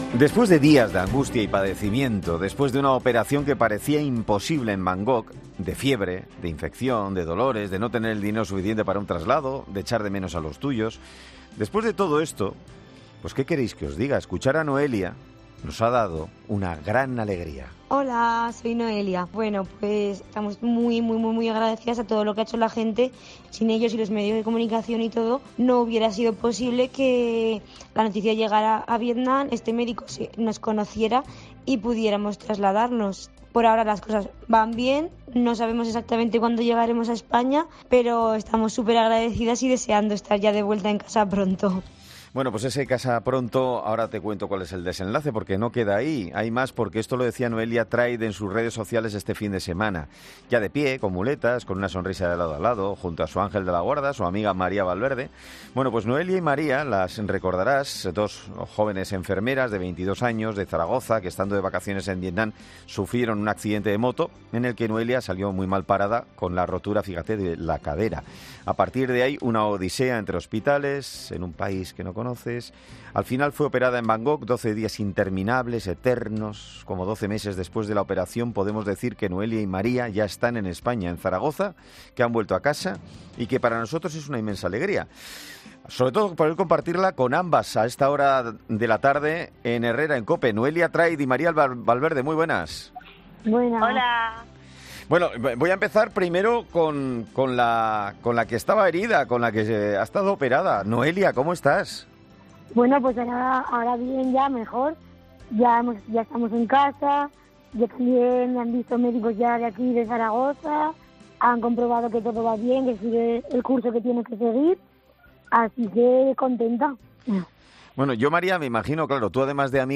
Este martes han sido entrevistadas en 'Herrera en COPE', donde han dicho que "están mejor".